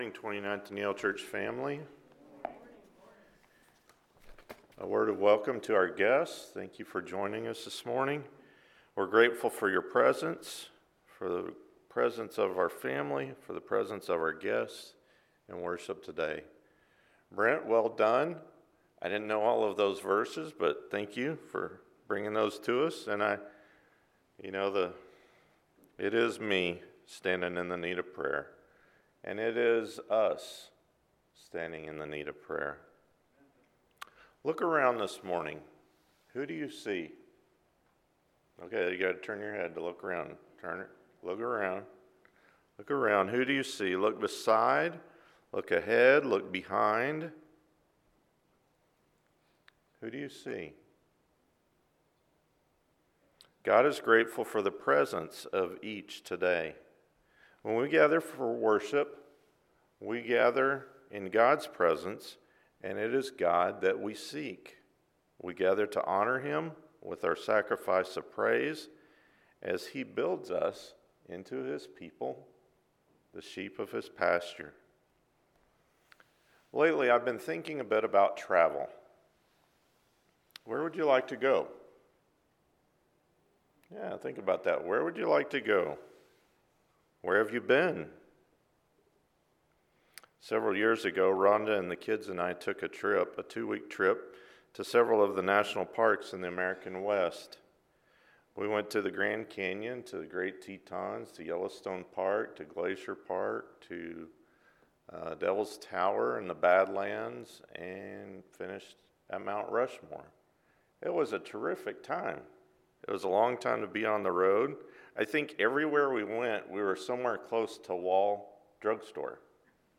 On the Road Together with Abraham – Sermon